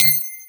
edm-perc-39.wav